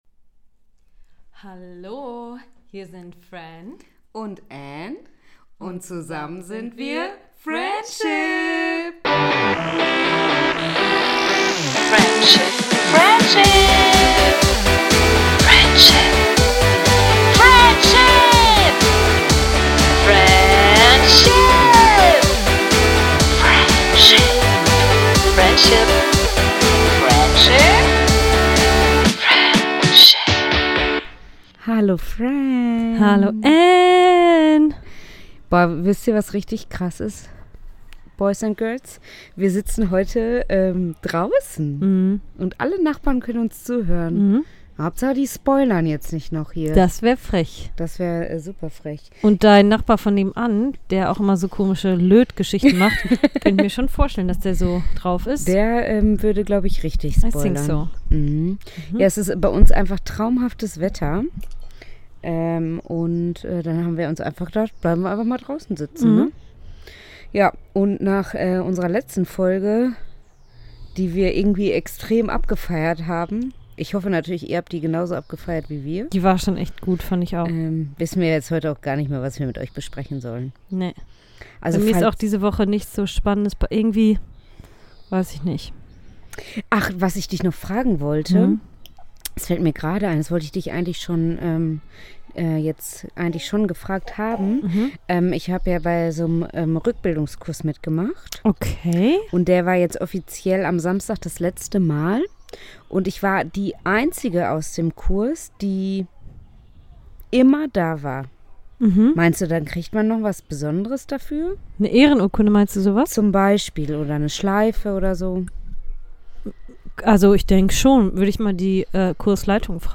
Bei diesem guten Wetter kriegt man doch direkt gute Laune und somit haben wir das Podcaststudio nach draußen verlegt!
Also hört rein und holt euch eine Portion Vögel- und Baby-Gezwitscher ab.